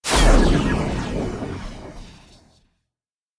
Звуки магии
Сгусток мощи устремился в сторону оппонента